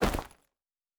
Bag 06.wav